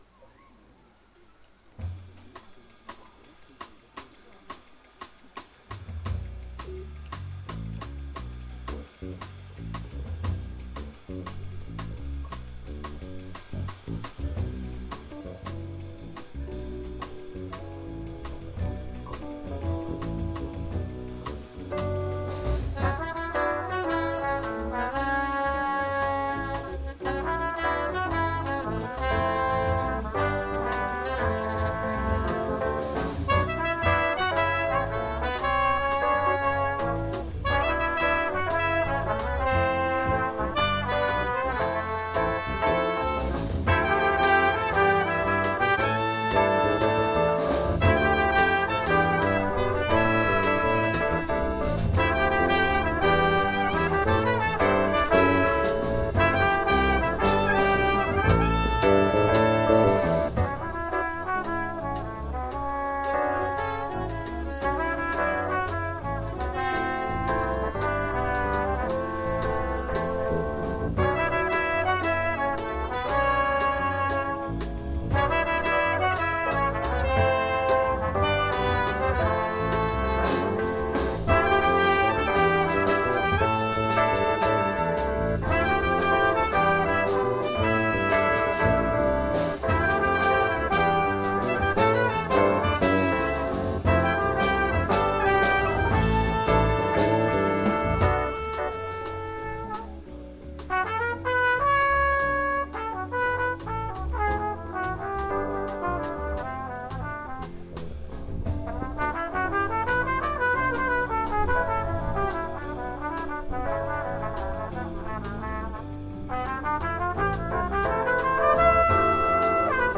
Jedes Jahr im August geben sich Musiker des Vereins auf der herrlichen Freilichtbühne des Fürther Stadtparks ein Stelldichein.
Die Tonqualität ist sehr einfach und der Wind weht manchmal,
saxophon
trumpet
mallets
keyboards
e-bass
drums